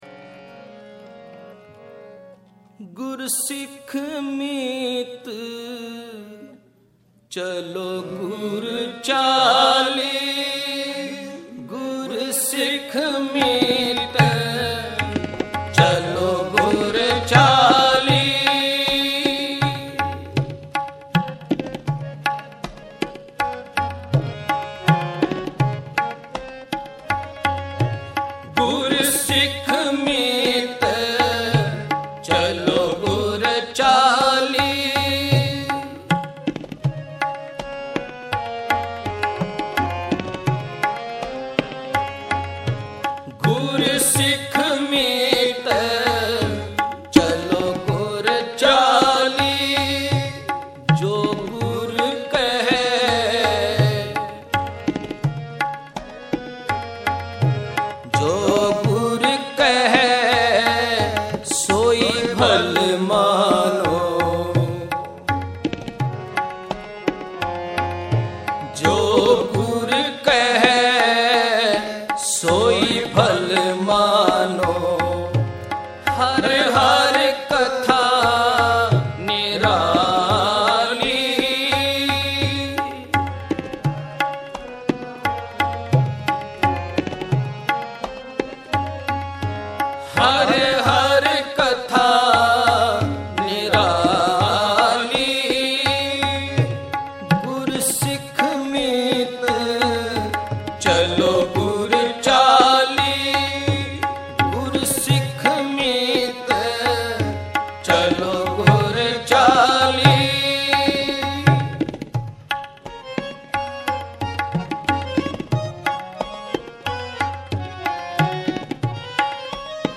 Genre: Shabad Gurbani Kirtan Album Info